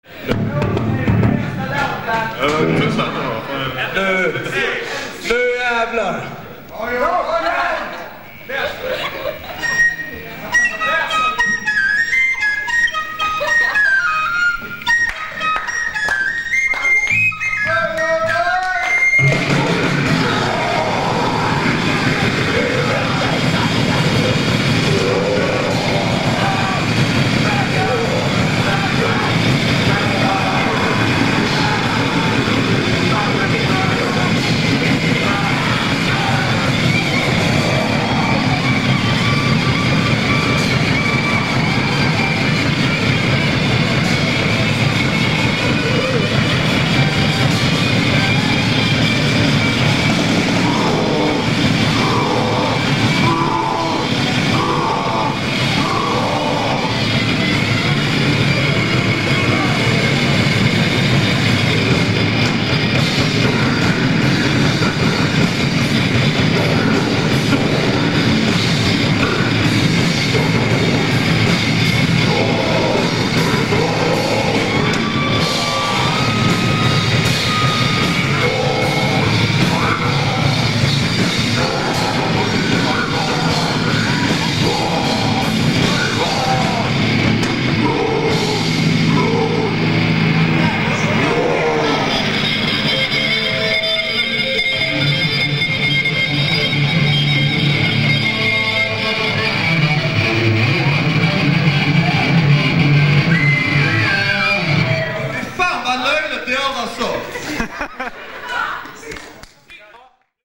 Luckily, the last concert was caught on videotape, along with four live tracks available below.
vocals
vocals, flute
guitar
slapp bazz
percussion